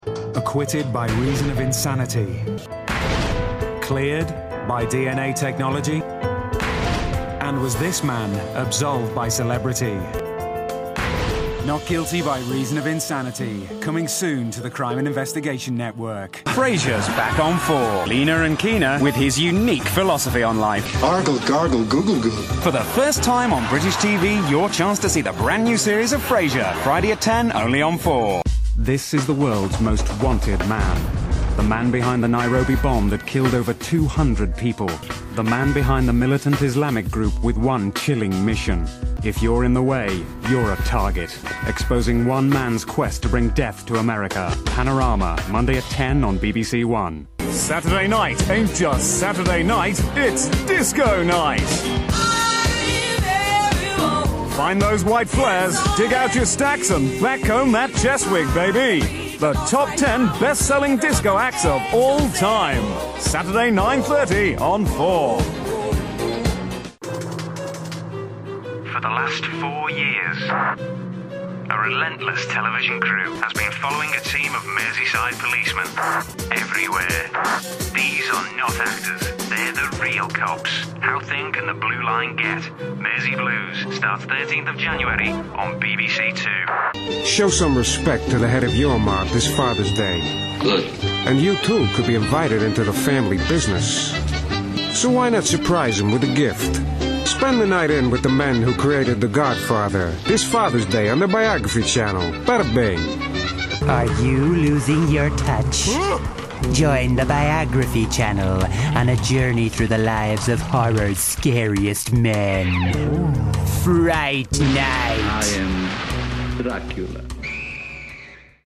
• Male